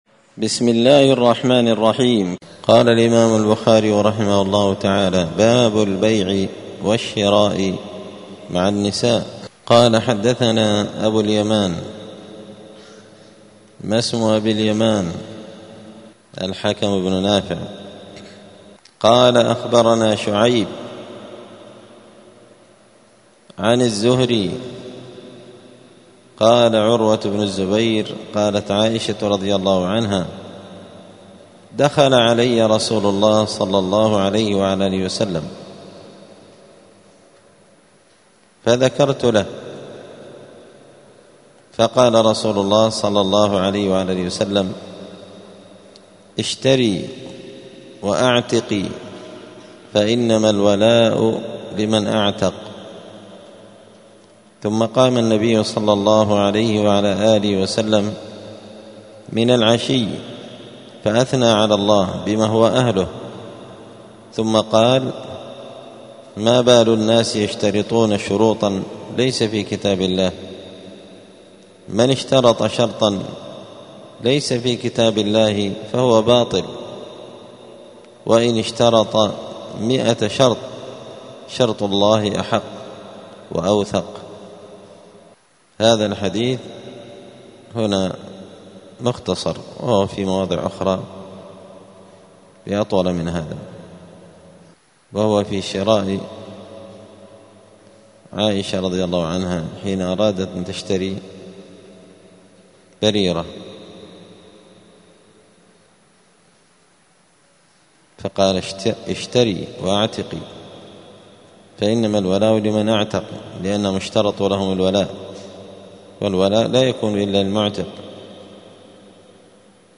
دار الحديث السلفية بمسجد الفرقان قشن المهرة اليمن
الأثنين 23 جمادى الأولى 1446 هــــ | الدروس، دروس الحديث وعلومه، شرح صحيح البخاري، كتاب البيوع من شرح صحيح البخاري | شارك بتعليقك | 23 المشاهدات